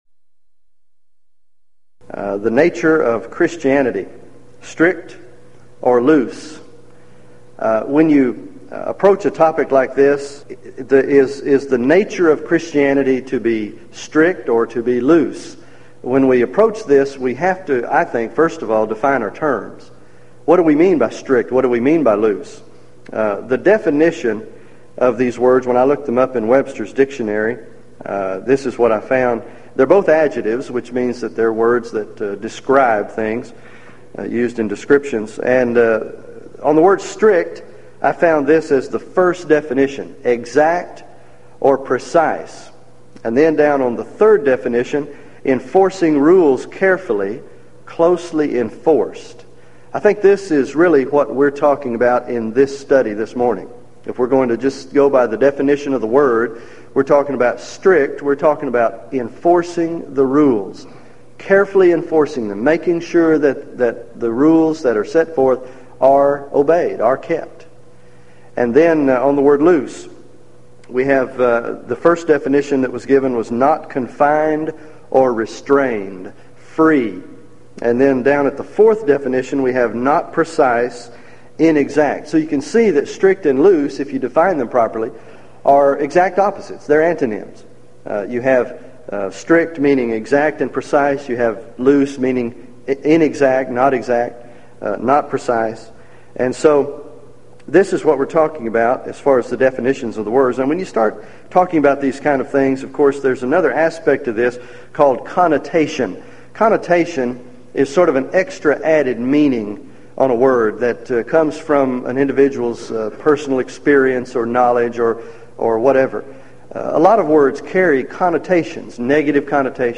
Event: 1997 Gulf Coast Lectures
lecture